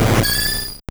Cri de Yanma dans Pokémon Or et Argent.